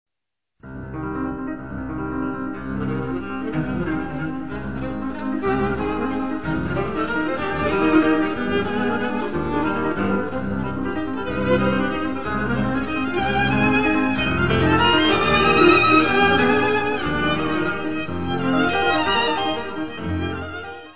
Área:  Música Clássica
Quatuor nº1 pour piano et cordes in C minor.